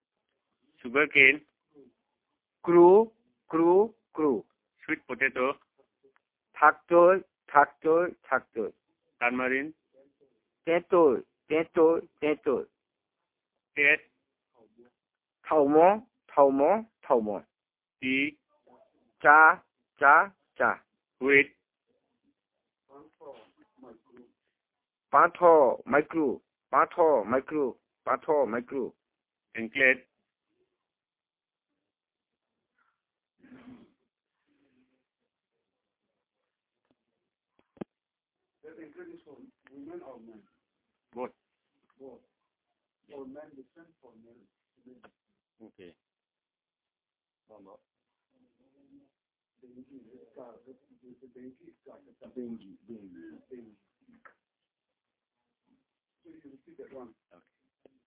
dc.coverage.spatialDepachrera, Gumoti
dc.description.elicitationmethodInterview method
dc.type.discoursetypeWord list elicitation